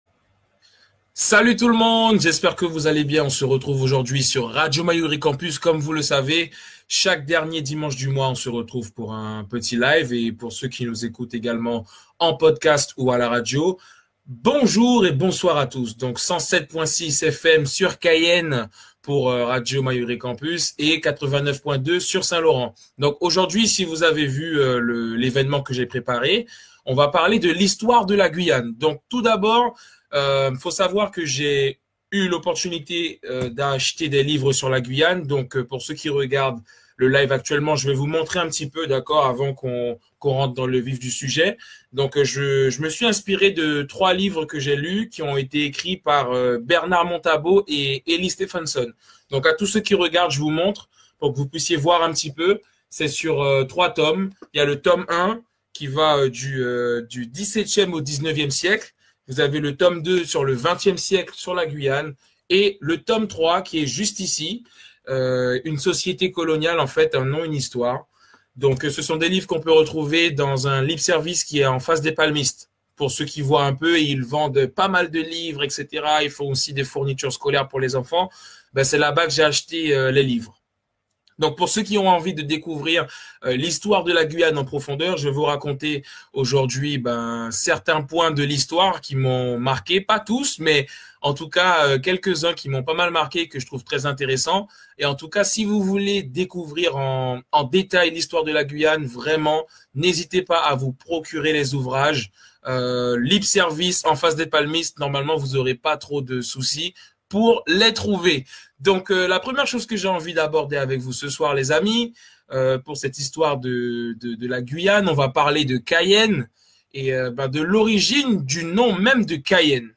Dans ce live, il partage avec vous les points de l'histoire qui l'ont le plus marqués et intrigués